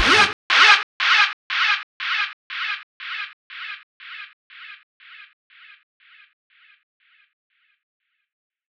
Vip Stab.wav